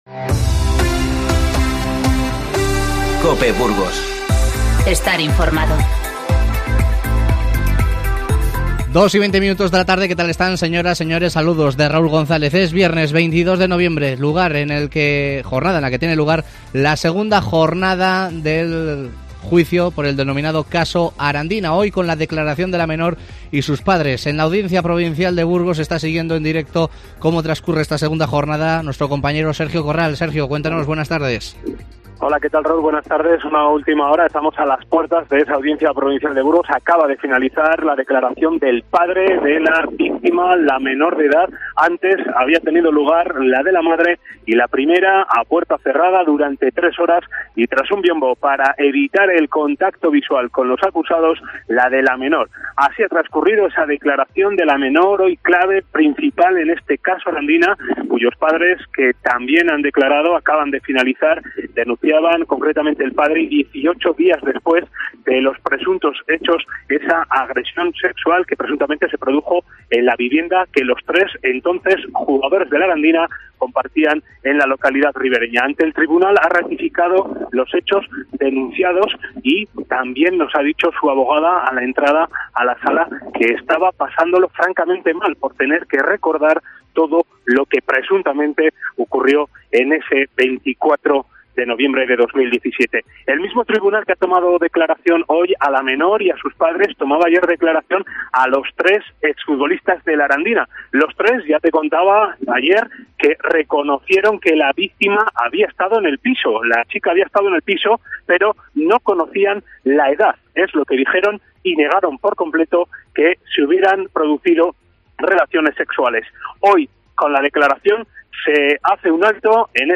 INFORMATIVO Mediodía 22-11-19